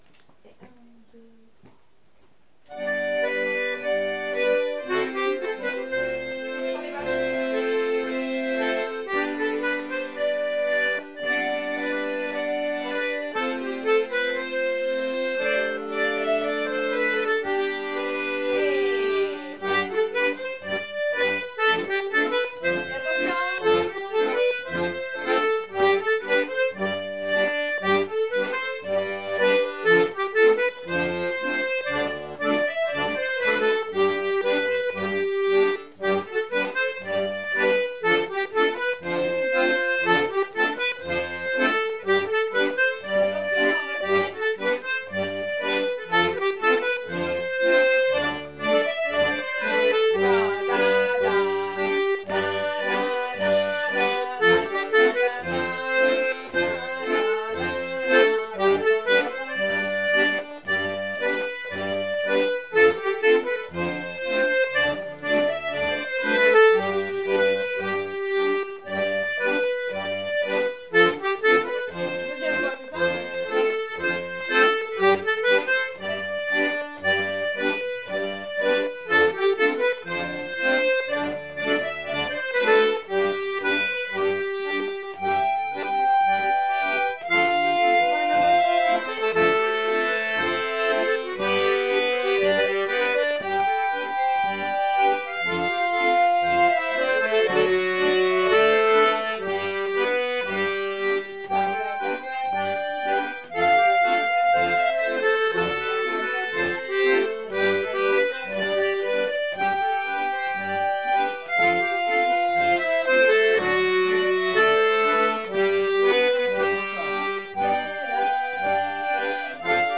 l'atelier d'accordéon diatonique
Accompagnements rythmiques, et secondes voix pour enrichir des morceaux trad.
1) Scottish "les maris costauds"